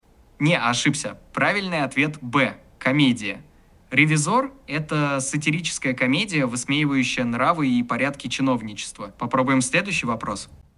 Как звучит голос (страшно реалистично)
Пример мужского голоса
Сейчас есть два голоса, оба звучат очень натурально, молодо и бодро.
ГигаЧат уместно расставляет интонации, по-настоящему тянет звуки и делает паузы на предыханиях, когда думает, вытягивает скорость и высоту речи, когда его перебили и нужно резко изменить ход мысли.
Единственное, звучание мужского голоса мне показалось более натуральным, в женском ещё чувствуется синтезированность.